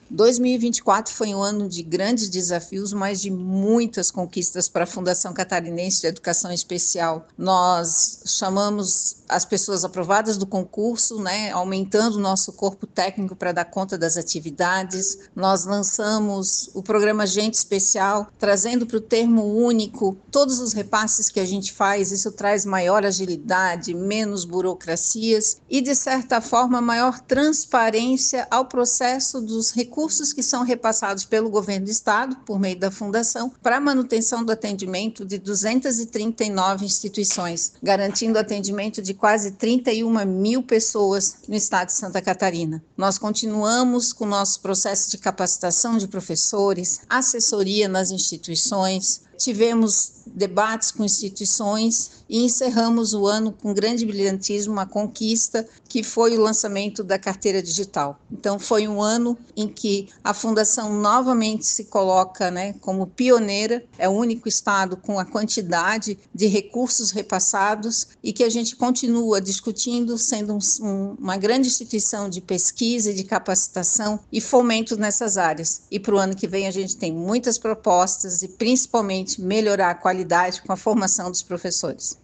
A presidente da FCEE, Jeane Rauh Probst Leite, fala das conquistas da Fundação em 2024 e dos desafios que vêm em 2025:
RETROSPECTIVA-SECOM-2024-Sonora-Presidente-FCEE.mp3